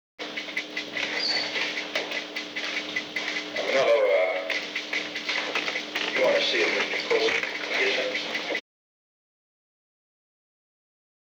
Conversation: 364-003
Recording Device: Old Executive Office Building
The Old Executive Office Building taping system captured this recording, which is known as Conversation 364-003 of the White House Tapes.